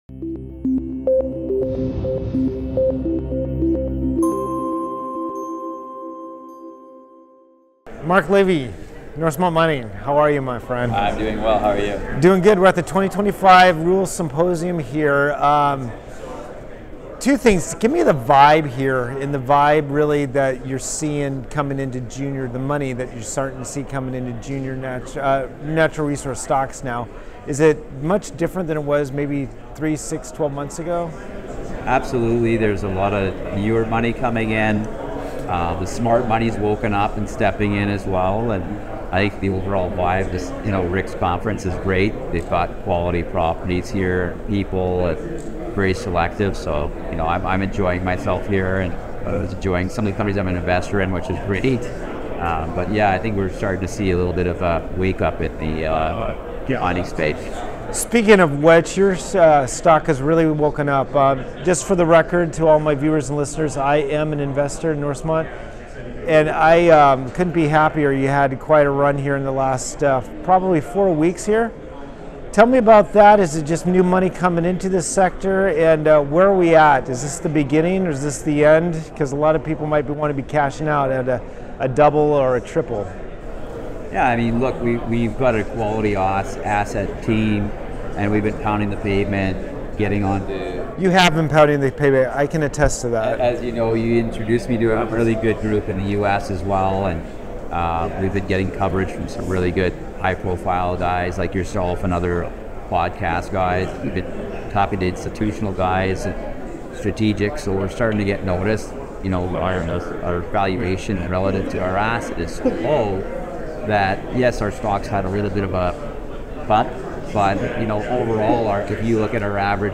Exploring the Junior Mining Boom: An Interview